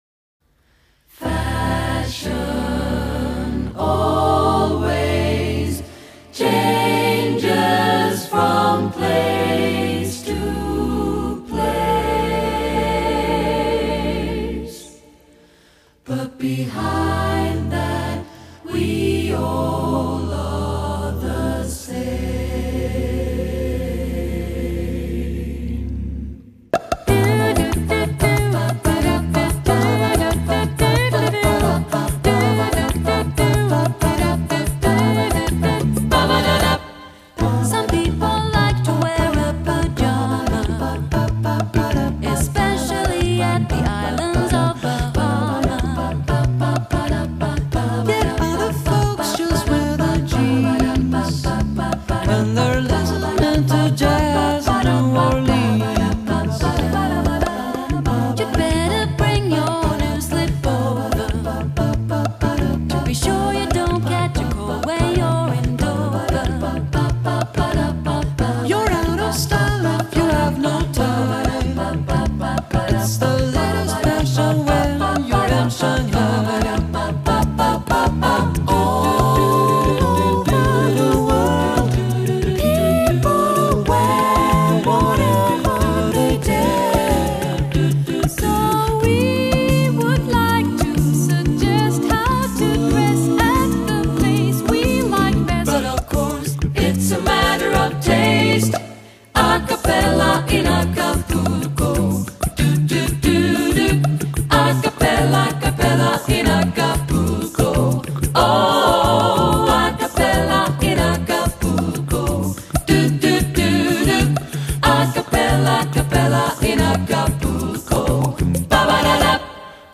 Accompaniment:      A Cappella
Music Category:      Vocal Jazz
with an exciting Latin groove